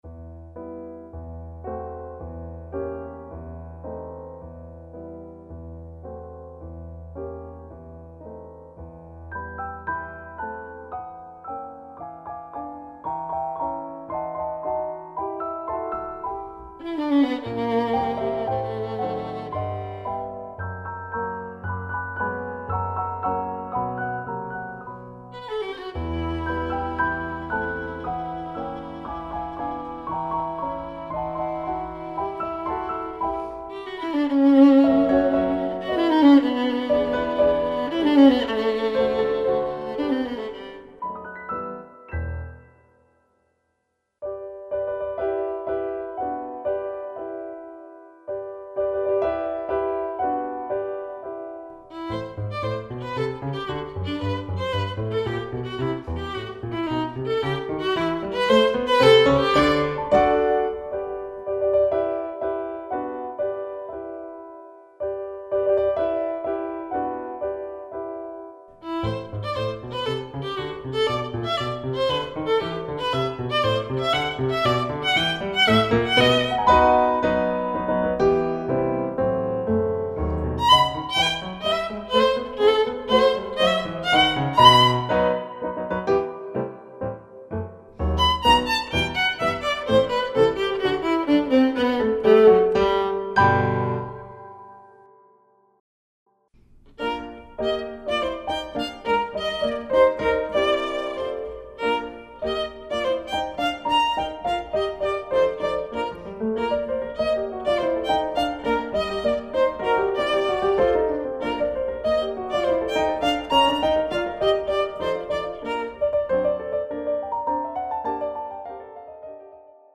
Duo
Casse noisette[3:19 min](Noël - Extraits)